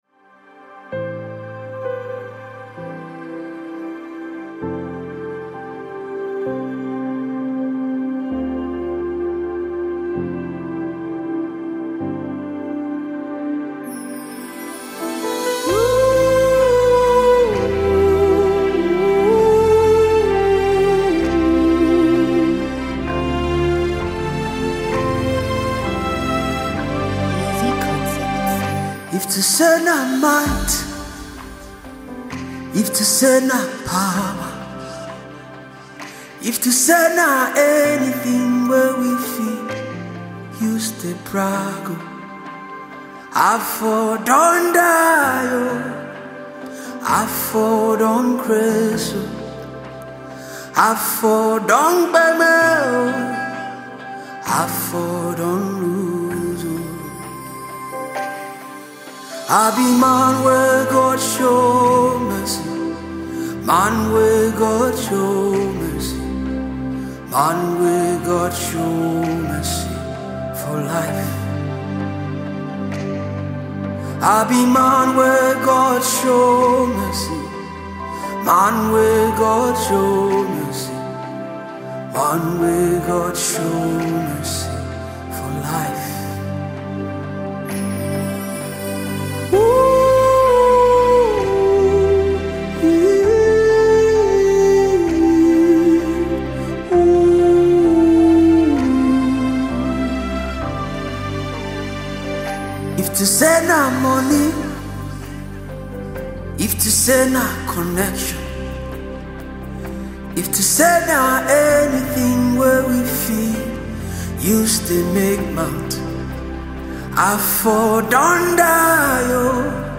Home » Gospel